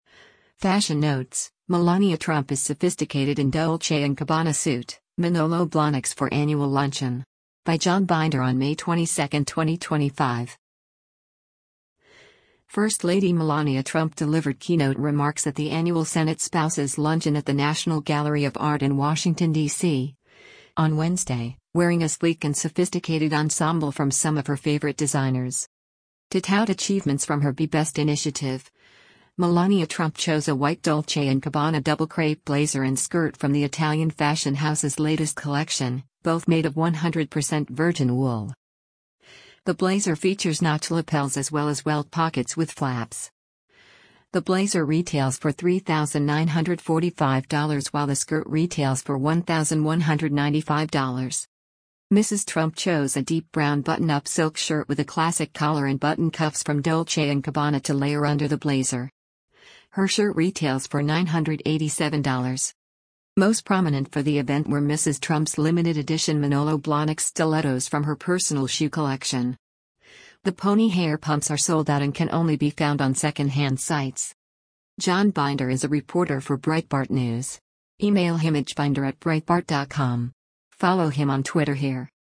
First Lady Melania Trump delivered keynote remarks at the annual Senate Spouses Luncheon at the National Gallery of Art in Washington, DC, on Wednesday, wearing a sleek and sophisticated ensemble from some of her favorite designers.